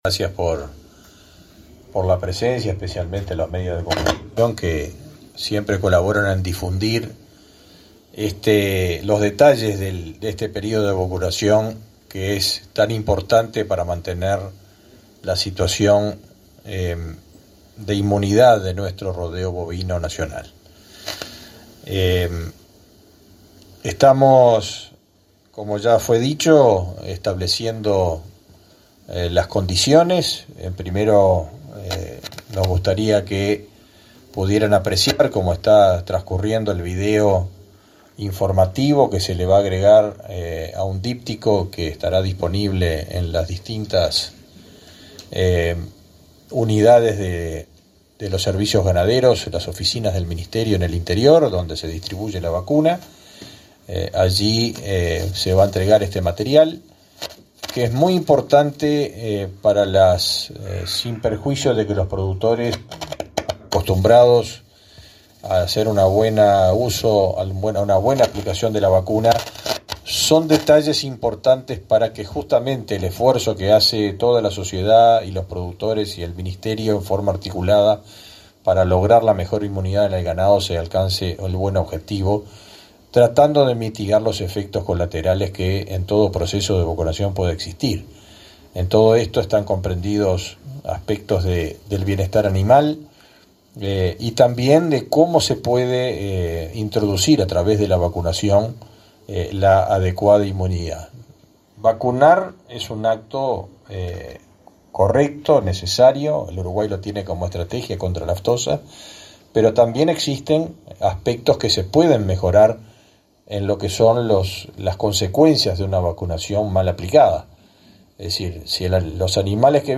Palabras del ministro de Ganadería, Fernando Mattos
El ministro de Ganadería, Fernando Mattos, encabezó este vienes 11, el acto de lanzamiento de la vacunación contra la aftosa 2022.